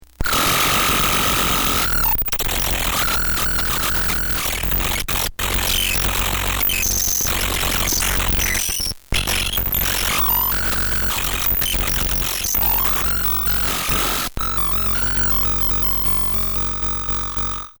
I made some changes to the electronics including pitch control, a 555 timer circuit that adds extra resistance in secret places, a speed control for the timer, 1/4″ output jack, and a touch-contact for good measure.
The last knob controls the rate at which the timer circuit strobes and for feedback, an LED inside the case that flashes at the same rate as the timer. The touch-contact creates a weird static reverb that varies depending on what the keyboard is connected to.